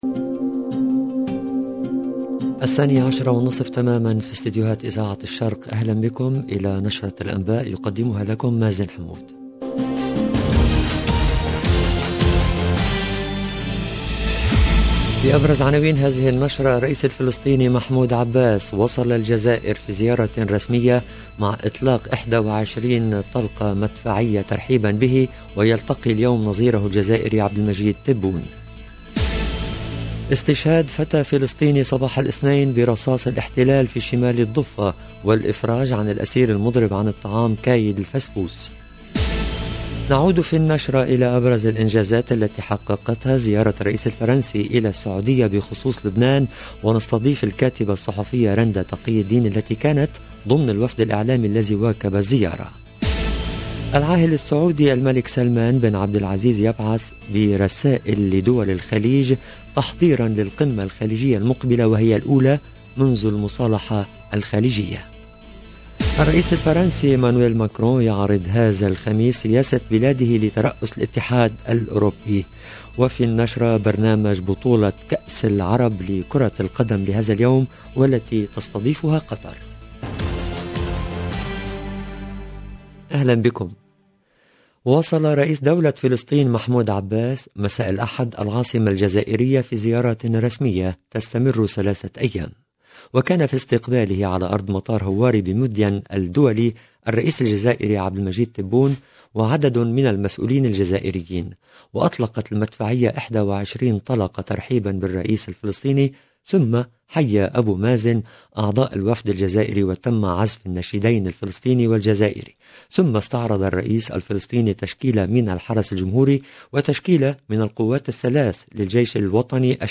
JOURNAL EN LANGUE ARABE